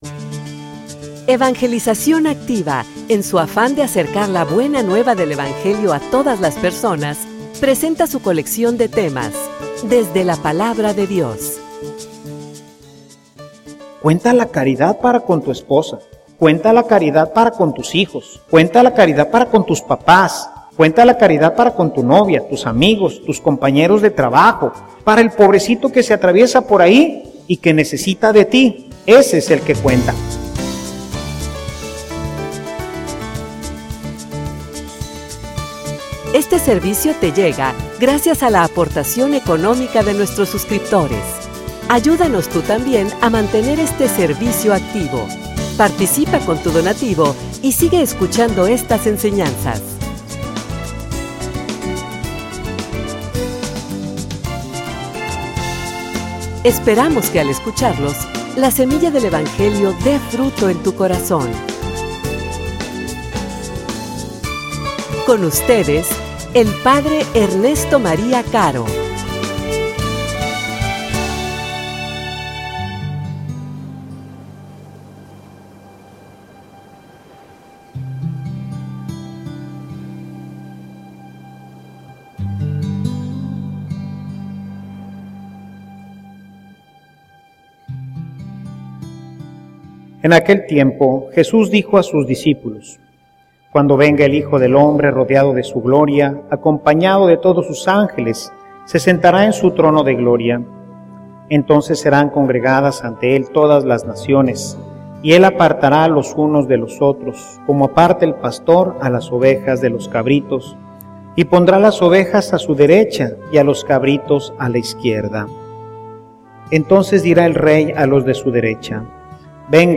homilia_Un_nuevo_ciclo.mp3